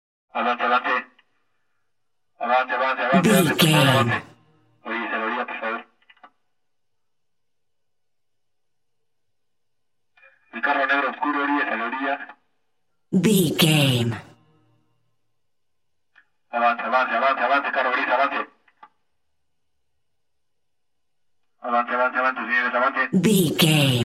Spanish Policeman Car Megaphone
Sound Effects
urban
chaotic
ambience